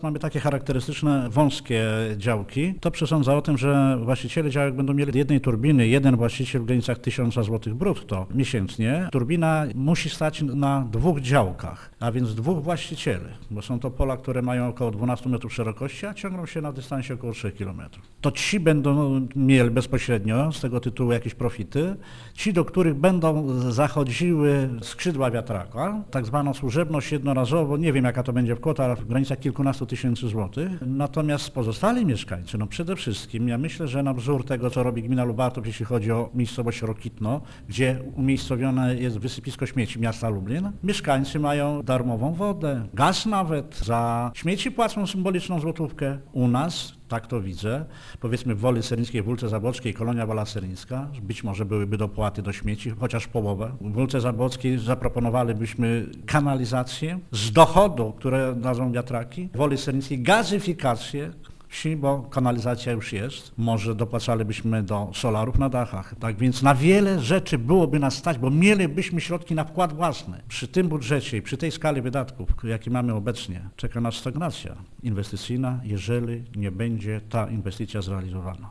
Wójt Gminy Serniki podkreśla, że wiatraki to nie tylko korzyści dla właścicieli działek, na których one staną: